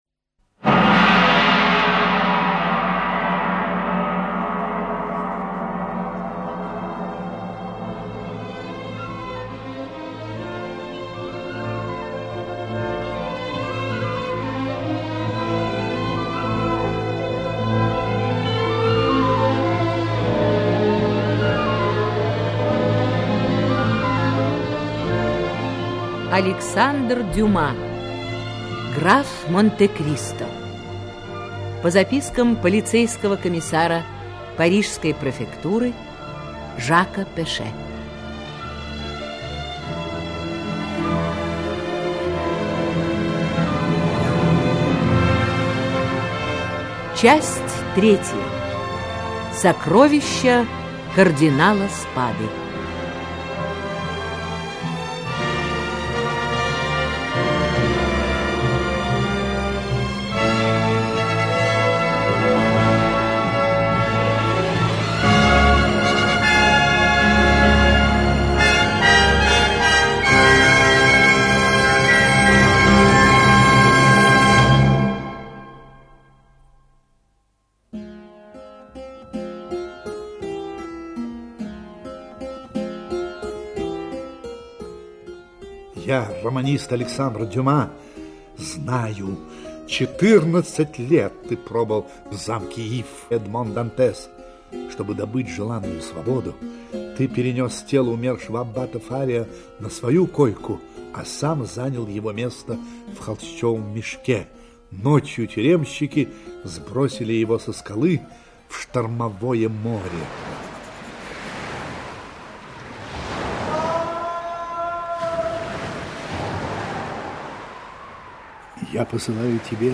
Аудиокнига Граф Монте-Кристо (спектакль) Часть 3-я. Сокровища кардинала Спады | Библиотека аудиокниг